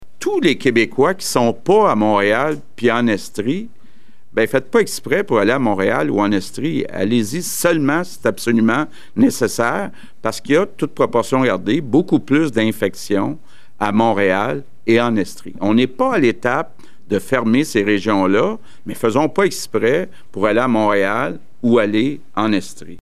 Dans son point de presse quotidien, le premier ministre François Legault a répété qu’il ne fallait pas voyager entre les régions, particulièrement en ce qui concerne Montréal et l’Estrie, les régions les plus touchées (voir le bilan par région ci-dessous).